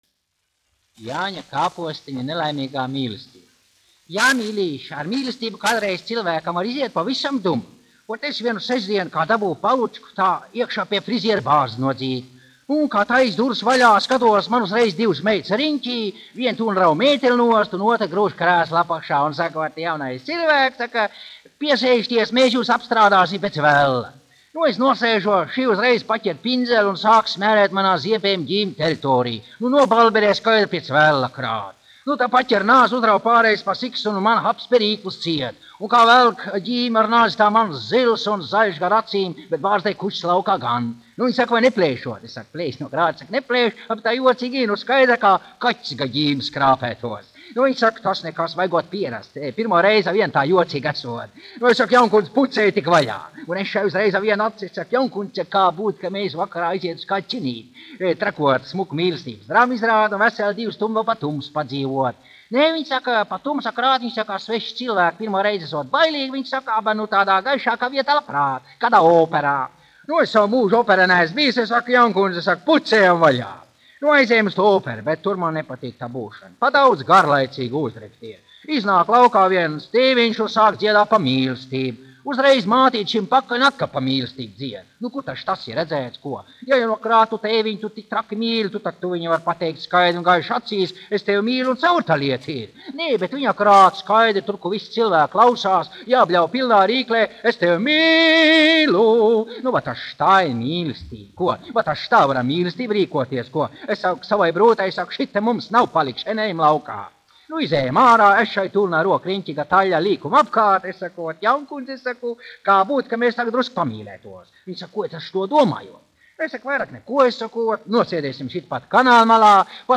Jāņa Kāpostiņa nelaimīgā mīlestība : humoristisks stāstiņš
1 skpl. : analogs, 78 apgr/min, mono ; 25 cm
Skaņuplate
Latvijas vēsturiskie šellaka skaņuplašu ieraksti (Kolekcija)